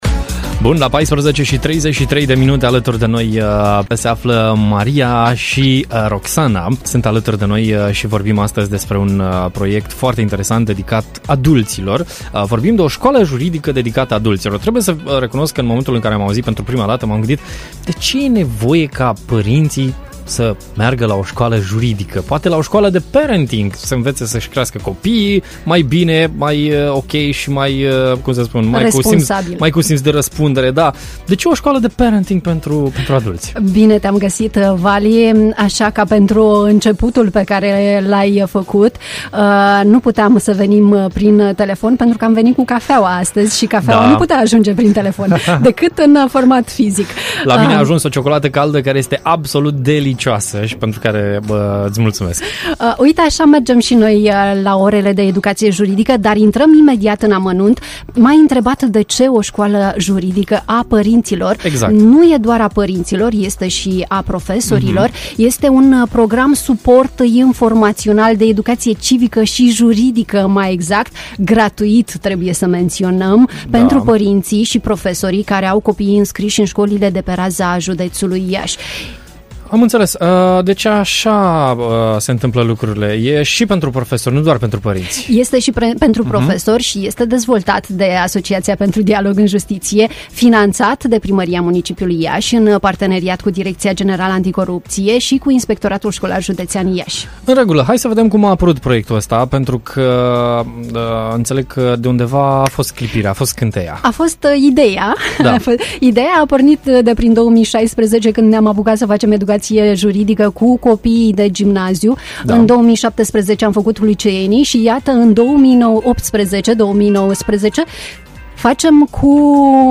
Am aflat detalii de la două persoane direct implicate în proiect